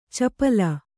♪ capala